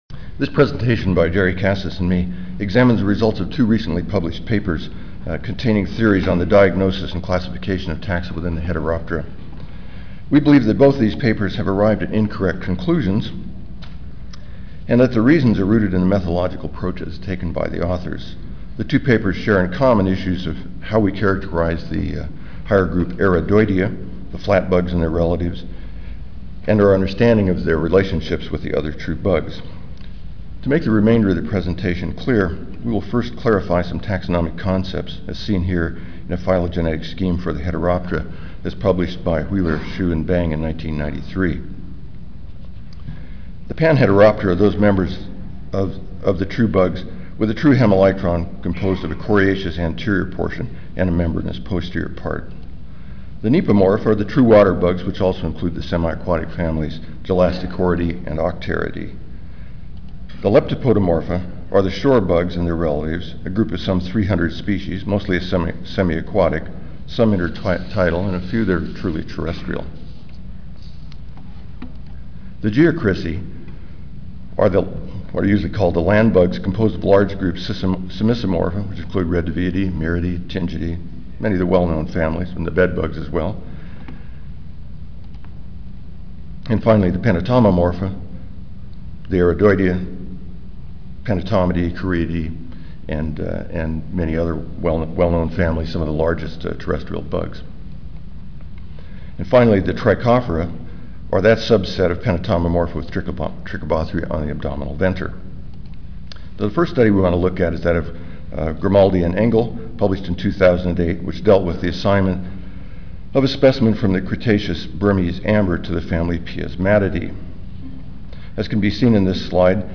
Ten Minute Paper (TMP) Oral